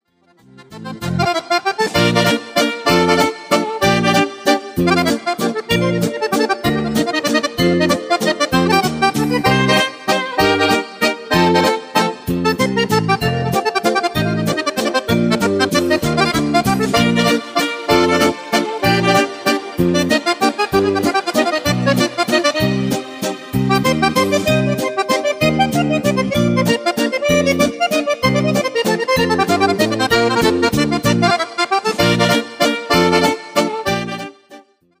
VALZER  (3.16)